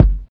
fsa_kick.wav